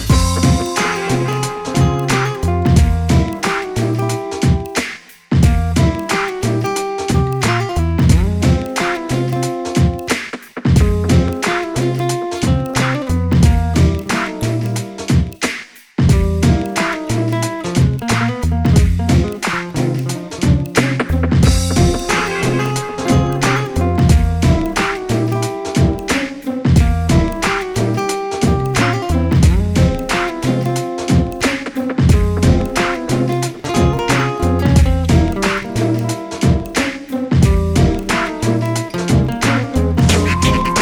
гитара
саундтреки
Electronic
без слов
Downtempo
инструментальные
Lounge